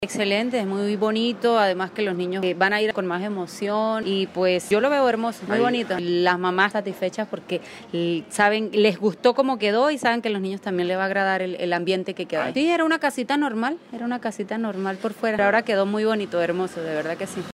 habitante del barrio Norte Bajo